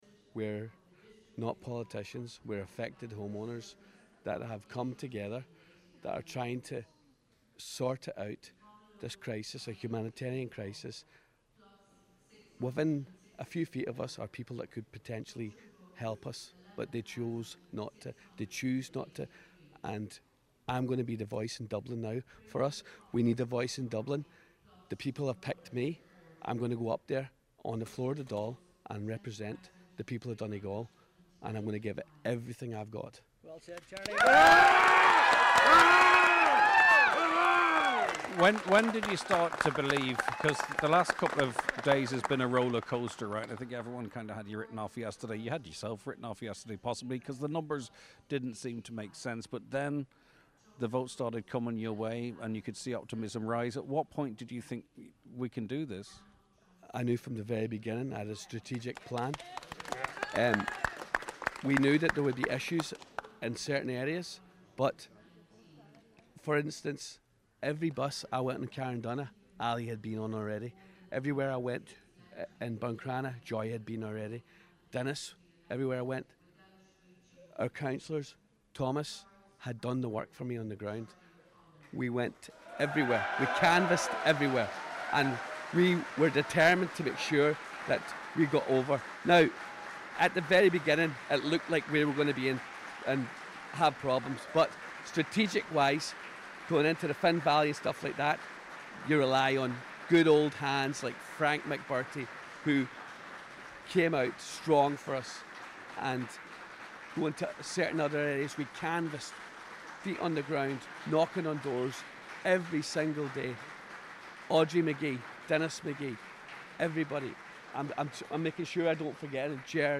He says he will give it his all: